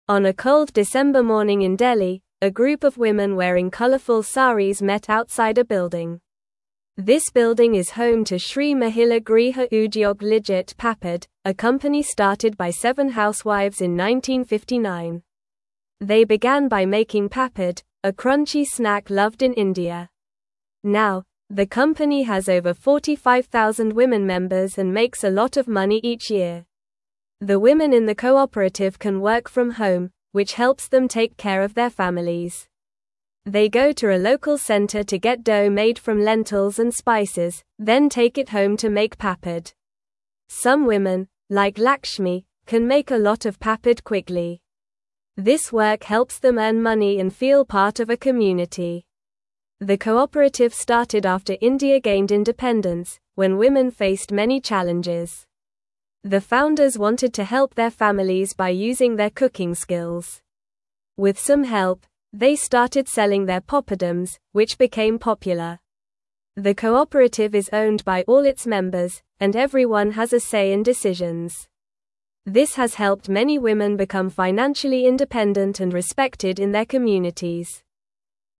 Normal
English-Newsroom-Lower-Intermediate-NORMAL-Reading-Women-in-India-Make-Tasty-Papad-Together.mp3